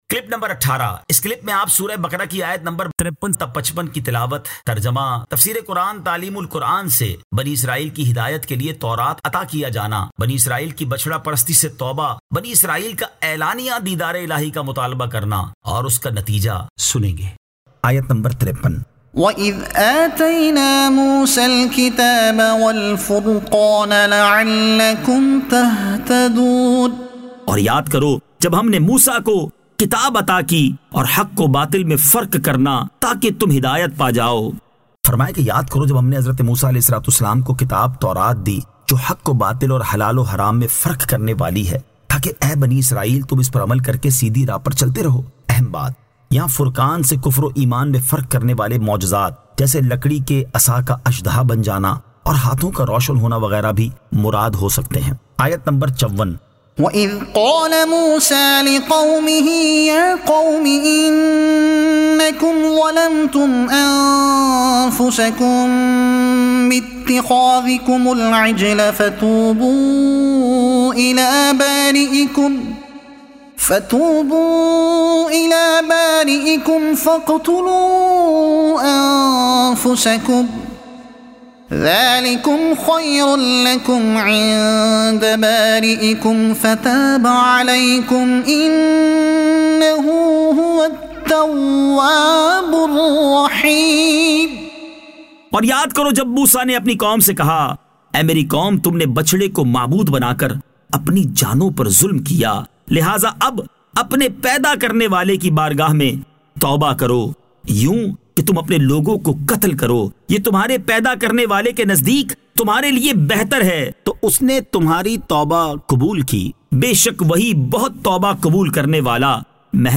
Surah Al-Baqara Ayat 53 To 55 Tilawat , Tarjuma , Tafseer e Taleem ul Quran